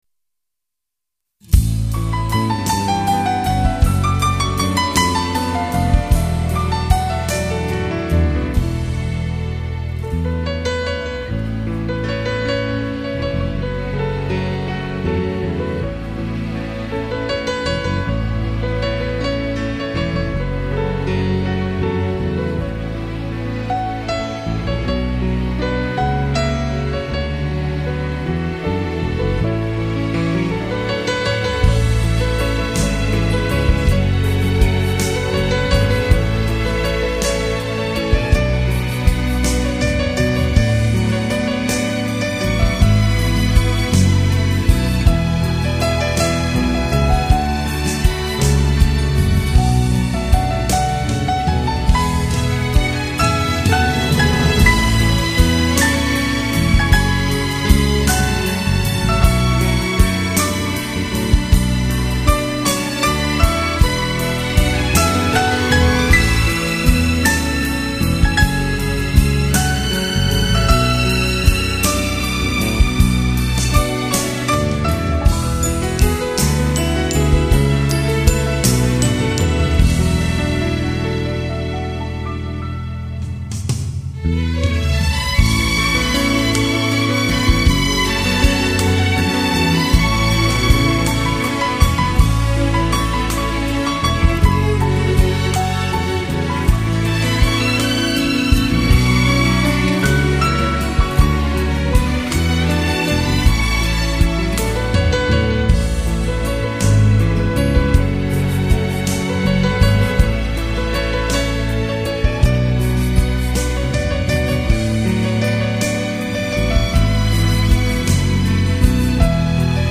样本格式    : 44.100 Hz;16 Bit;立体声